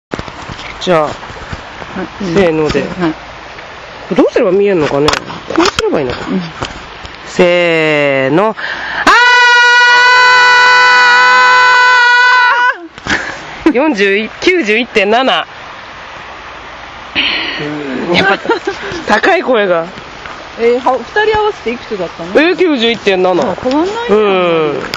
＞＞叫びその２（母娘二人バージョン）
叫び場データ「実家ちかくの河原」